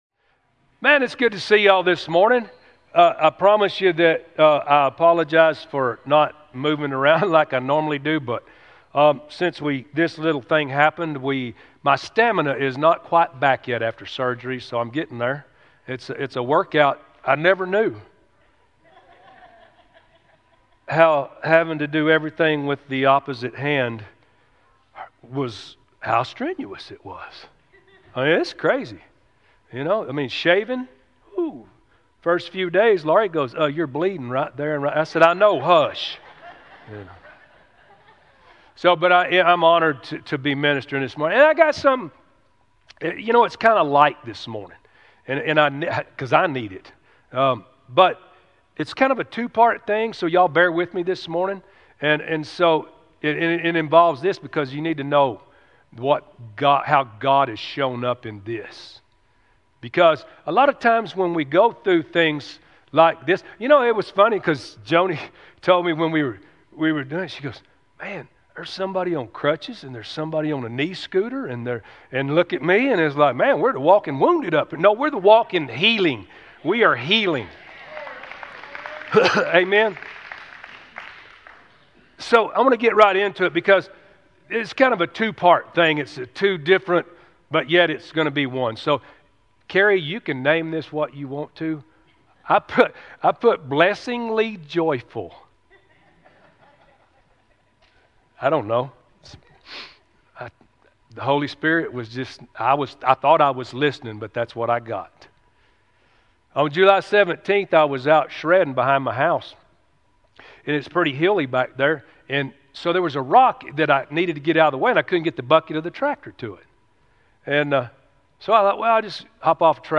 Sermons Archive ⋆ Williamson County Cowboy Church - Liberty Hill, TX